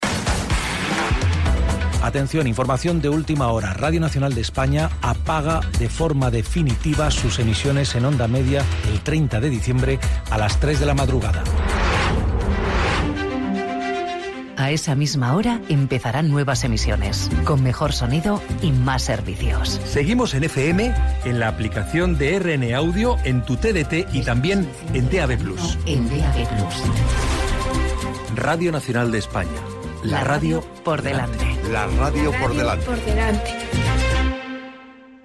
Tres promocions de l'apagada de les emissions en Ona Mitjana de RNE.